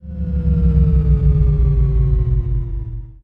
deceleration.ogg